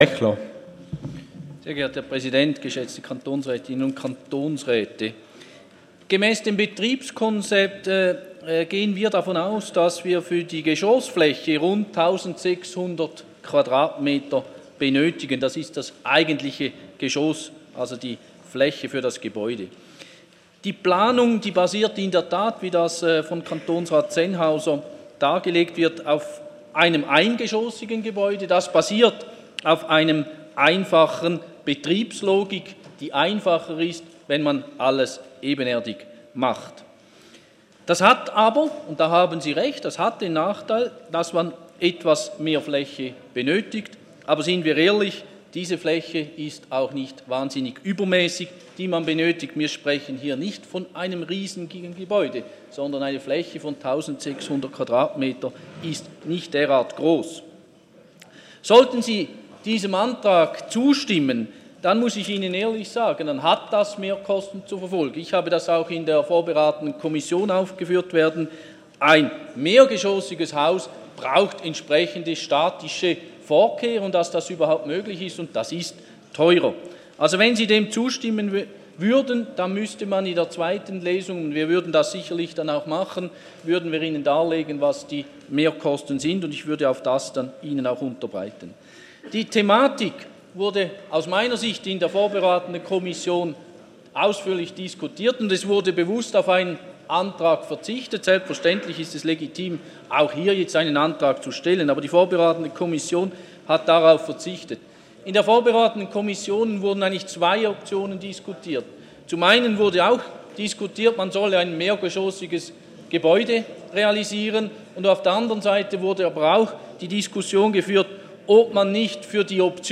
Session des Kantonsrates vom 23. und 24. April 2018